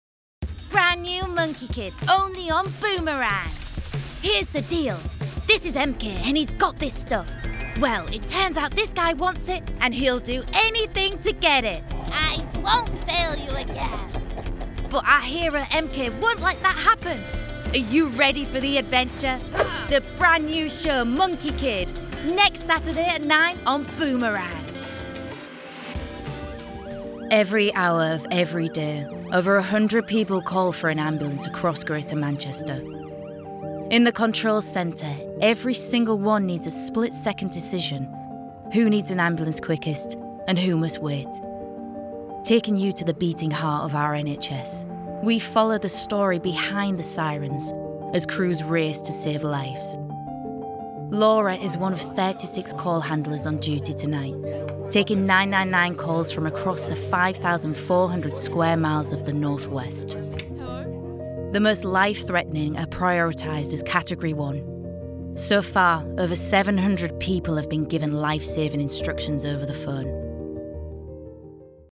Continuity Showreel
Female
Hull - Yorkshire
Northern